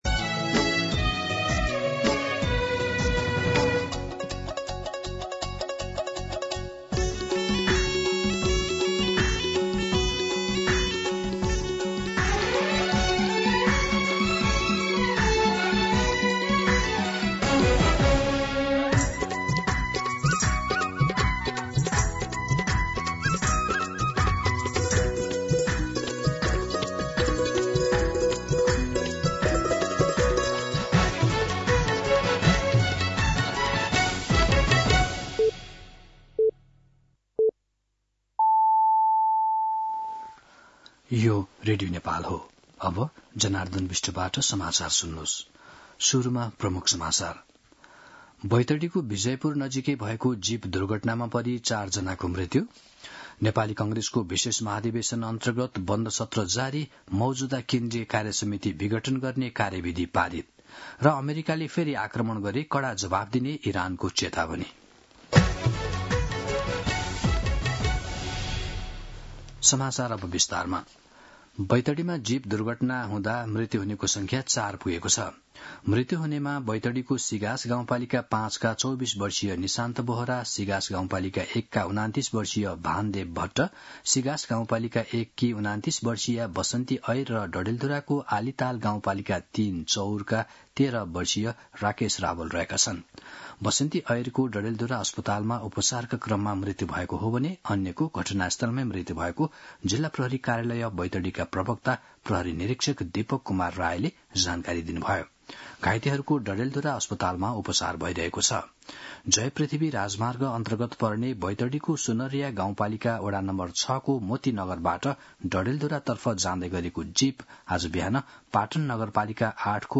दिउँसो ३ बजेको नेपाली समाचार : २८ पुष , २०८२